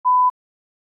ShortBeep.aif